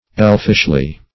elfishly - definition of elfishly - synonyms, pronunciation, spelling from Free Dictionary Search Result for " elfishly" : The Collaborative International Dictionary of English v.0.48: Elfishly \Elf"ish*ly\, adv. In an elfish manner.